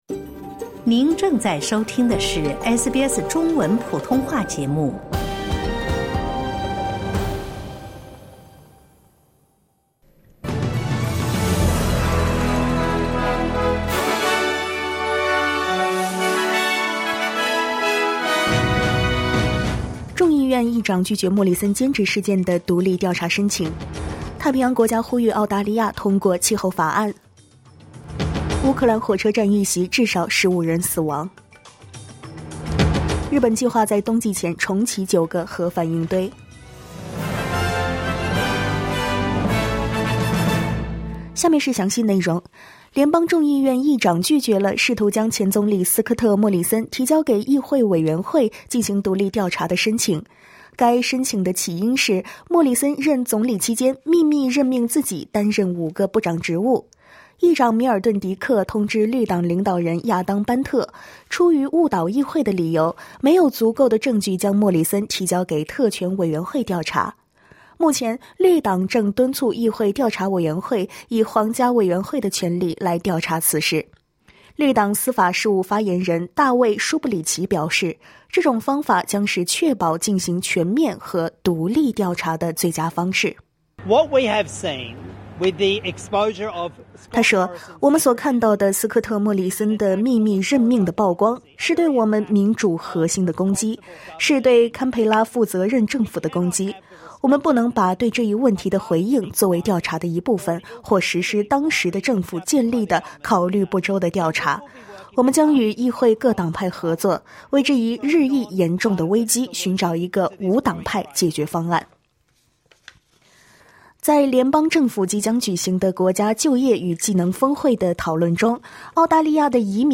SBS早新闻（8月25日）